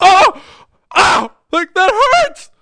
WELDER-GETSHOT1.mp3